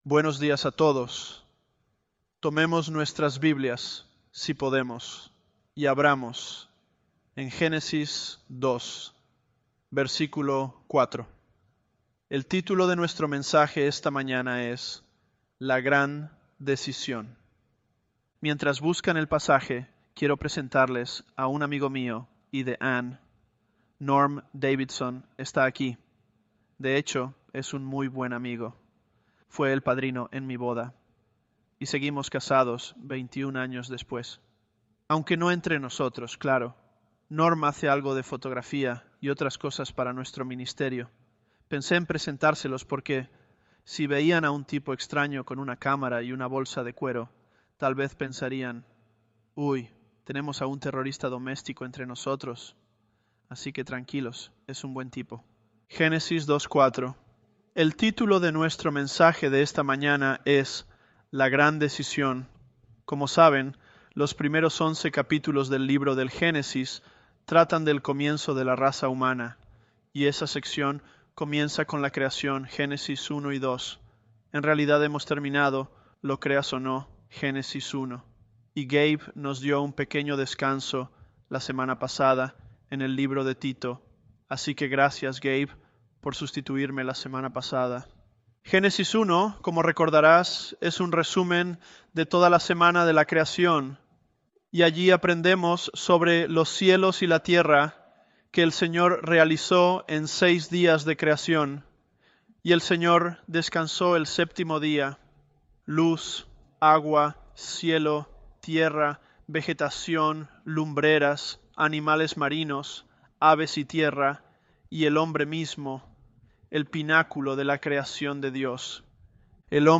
Sermons
ElevenLabs_Genesis-Spanish008.mp3